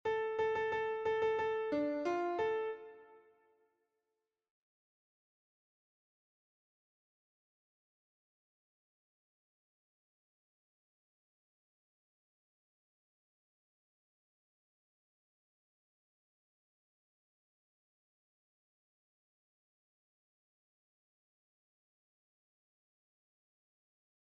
- Œuvre pour choeur à 4 voix d’hommes (TTBB) + soliste
Voix